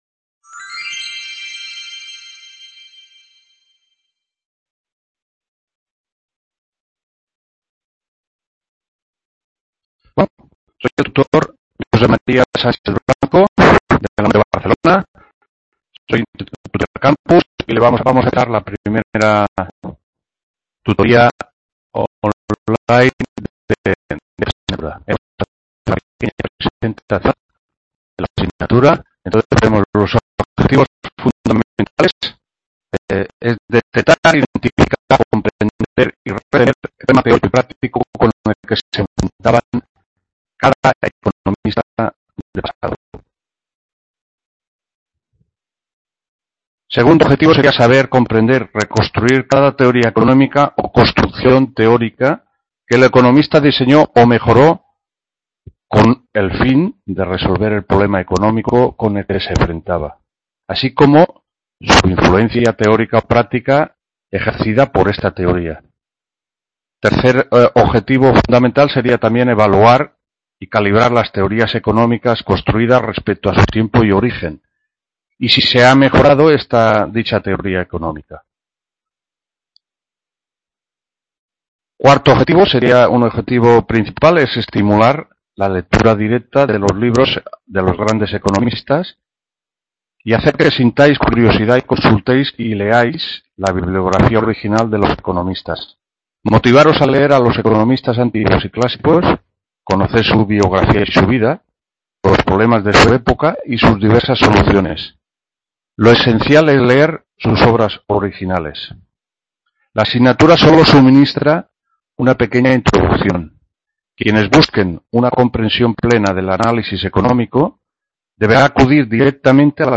1ª TUTORÍA HISTORIA DEL PENSAMIENTO ECONÓMICO- 14-05… | Repositorio Digital